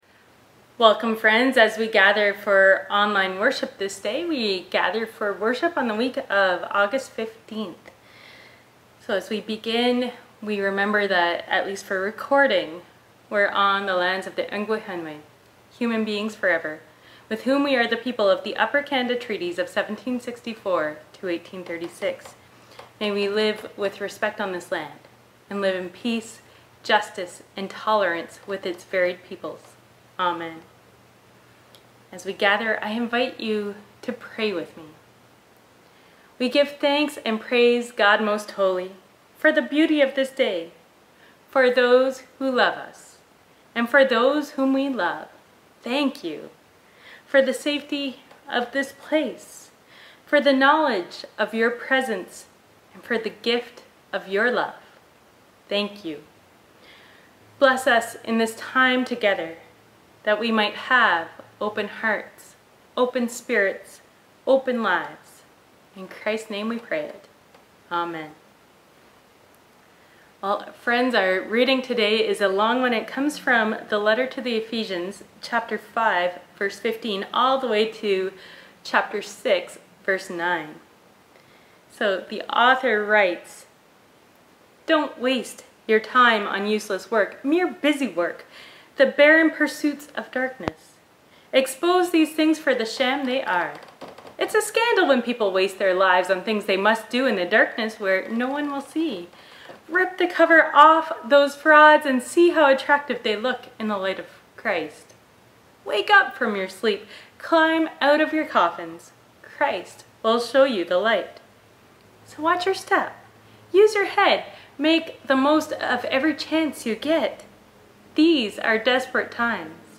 Passage: Ephesians 5:15-6:9 Service Type: Standard « Eleventh Sunday after Pentecost 2021 Christmas Eve Service 2021